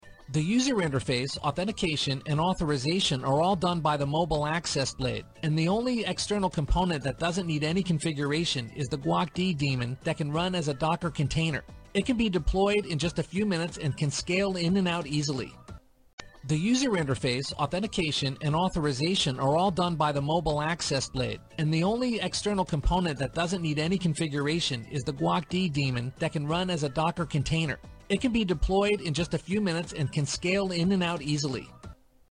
英语配音美式英语配音
• 男英5 美式英语 PPT手绘动画解说 激情激昂|沉稳|娓娓道来|科技感|积极向上|时尚活力|神秘性感|素人